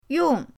yong4.mp3